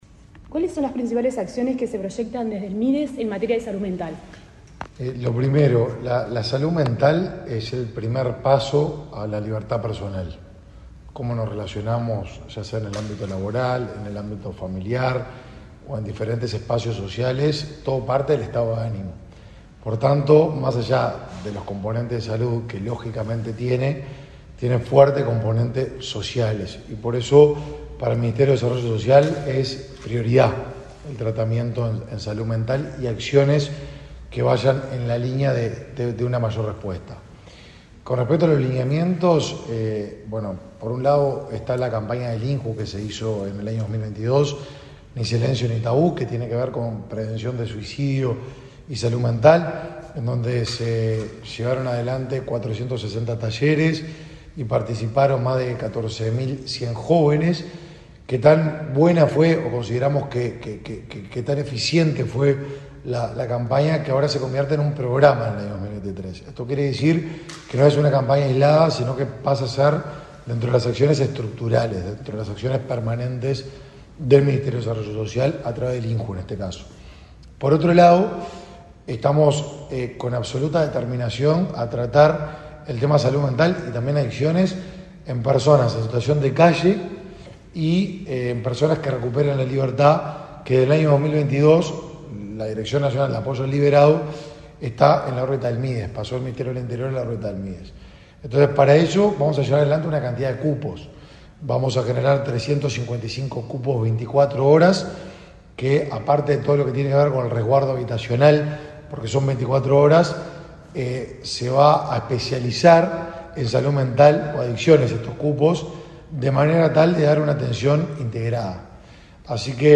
Entrevista al ministro de Desarrollo Social, Martín Lema